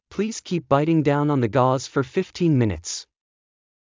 ﾌﾟﾘｰｽﾞ ｷｰﾌﾟ ﾊﾞｲﾃｨﾝｸﾞ ﾀﾞｳﾝ ｵﾝ ｻﾞ ｶﾞｰｽﾞ ﾌｫｰ ﾌｨﾌﾃｨｰﾝ ﾐﾆｯﾂ
US-bite-15min.mp3